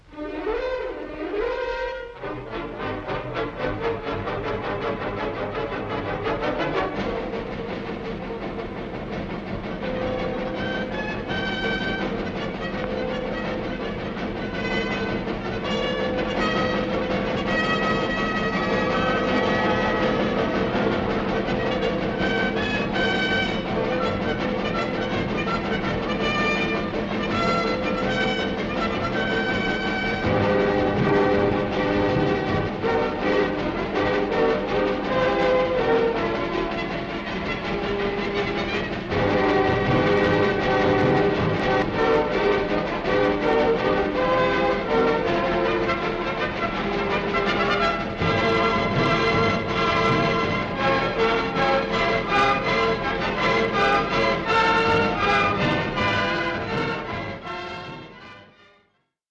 Original Track Music (1.00)